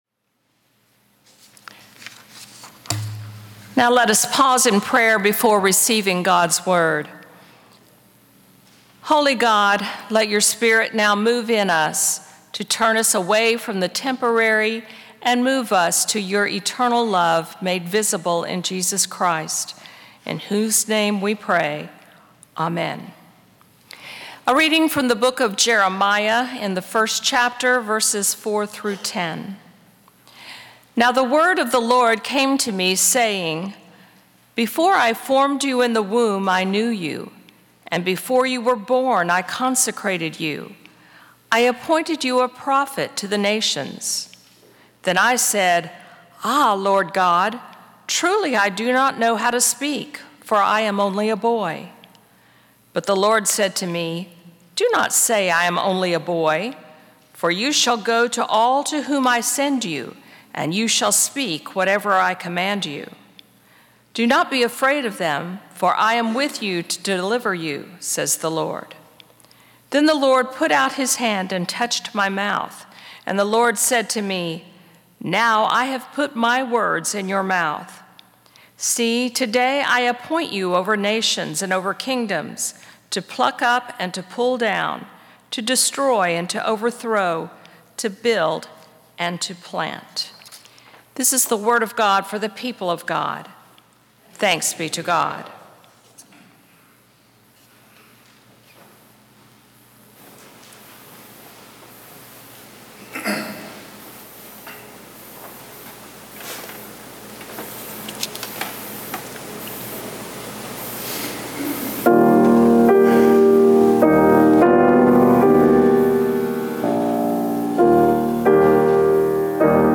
“God’s Healing Touch” – Sermon from August 24, 2025